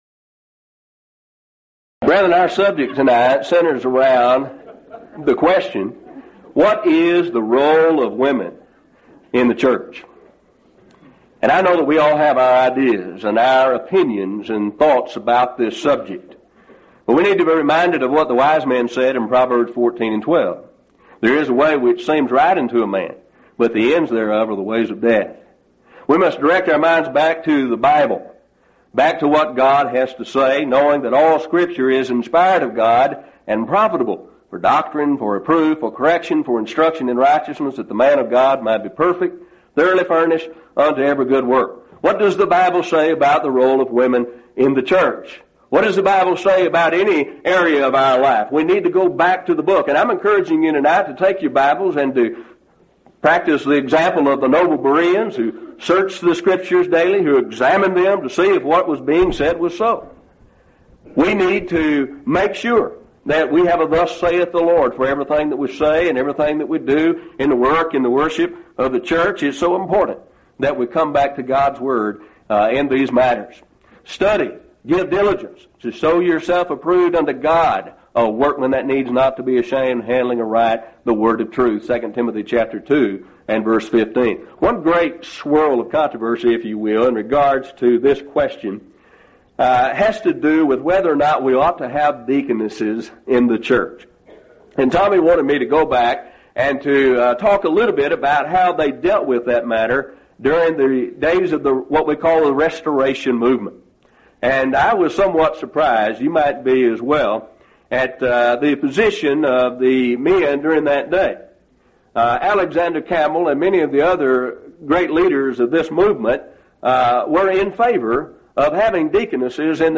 Event: 2nd Annual Lubbock Lectures
If you would like to order audio or video copies of this lecture, please contact our office and reference asset: 1999Lubbock35